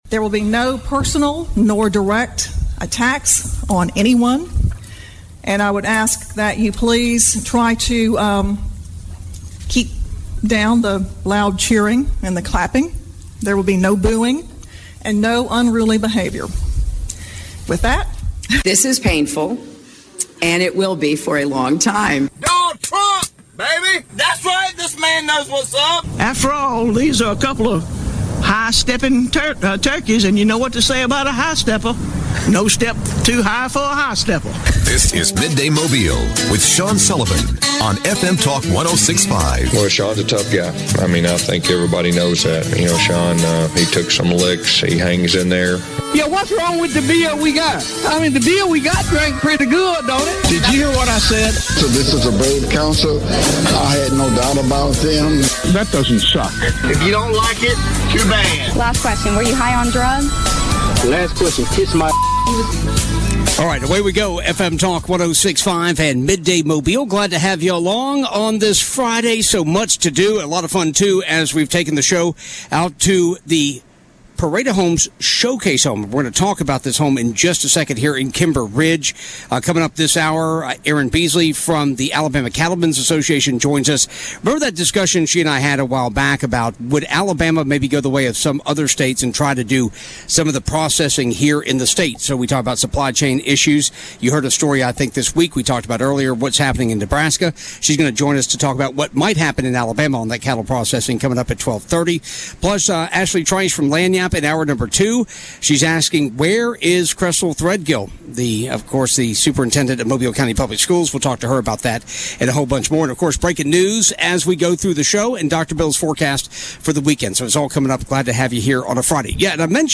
Midday Mobile - Live from Kimber Ridge Parade of Homes